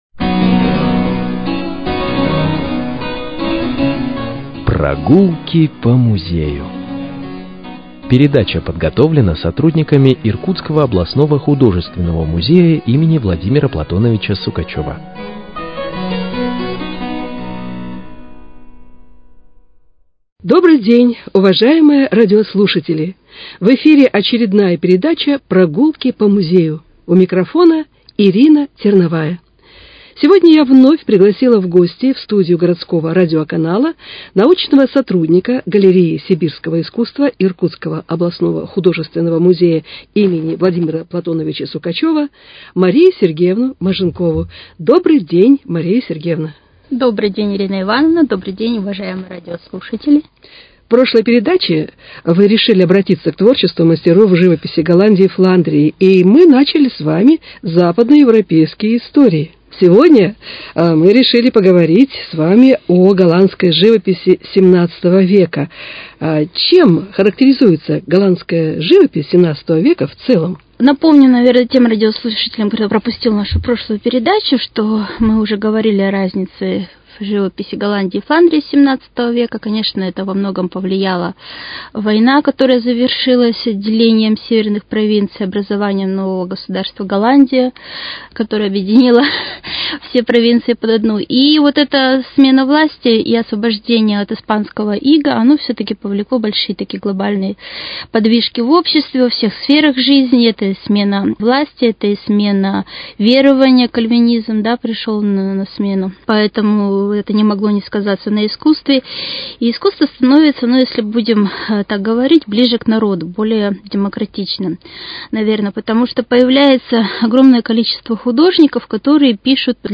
беседует с научным сотрудником